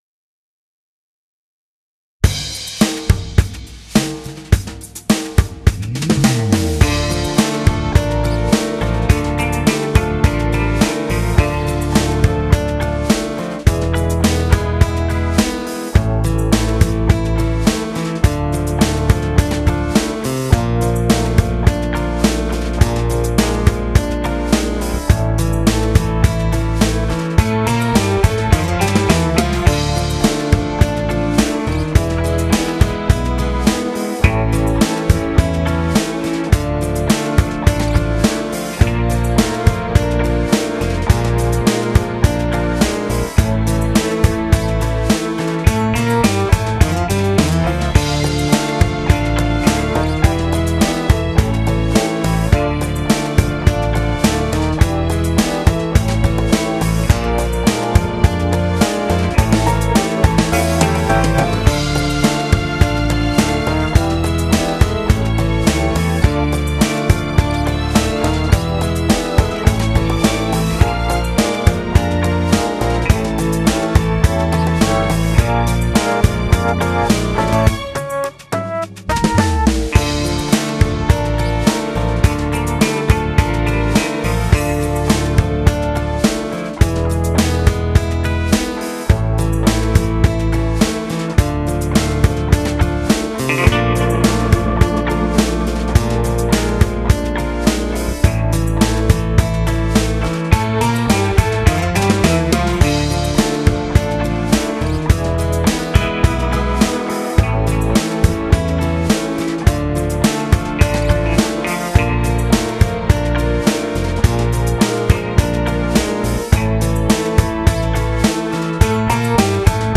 Genere: Moderato Lento
Scarica la Base Mp3 (2,96 MB)